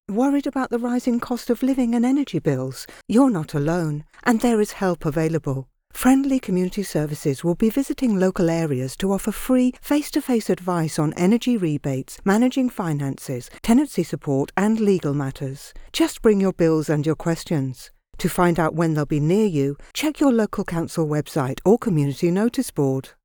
A calm, educated and believable tone. Authentic and natural.
Radio Advert
British Neutral/RP